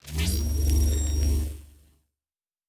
Shield Device 5 Start.wav